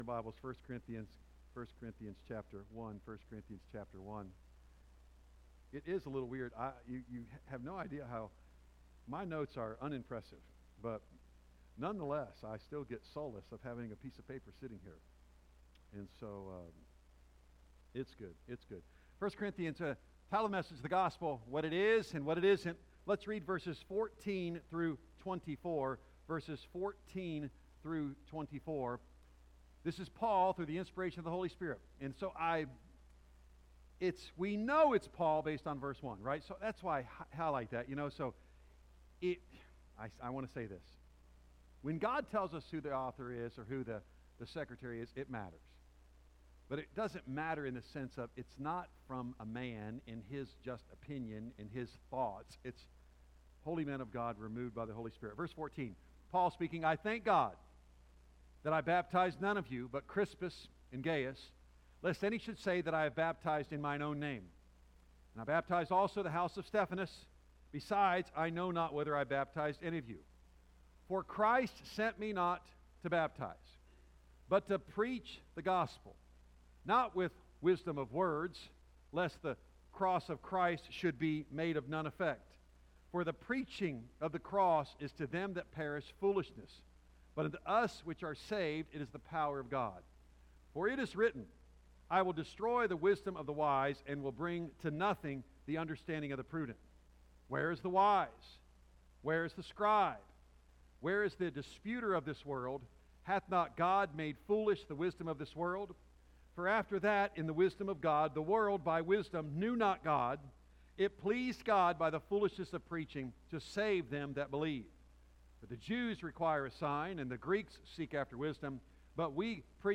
A message from the series "1 Corinthians."